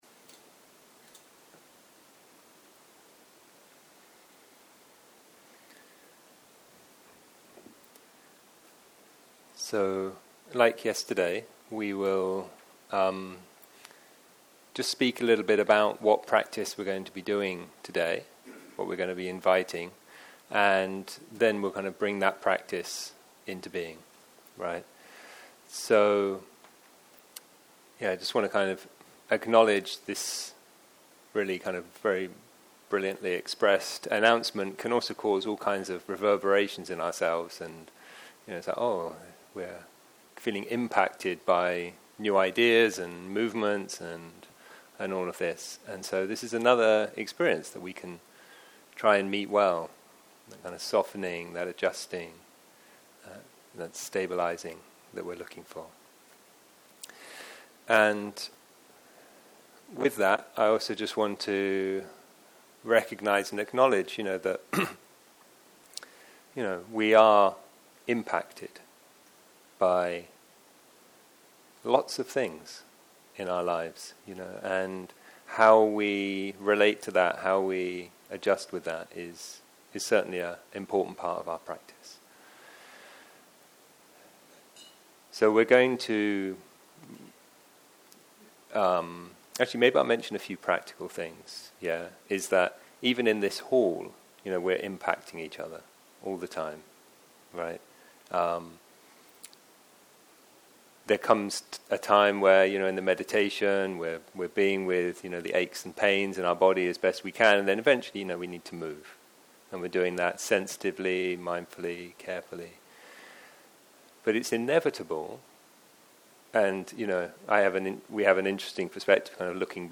בוקר - הנחיות מדיטציה + מדיטציה מונחית - Meeting well.
דף הבית › ספריה › ספרית הקלטות בוקר - הנחיות מדיטציה + מדיטציה מונחית - Meeting well.